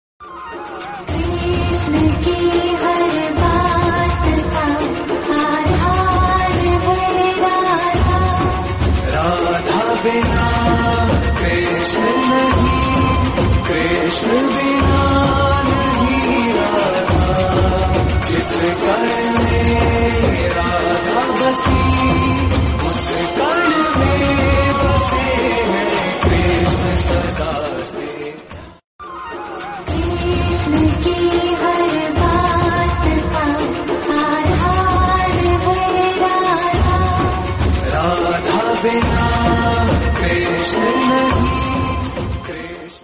Category Devotional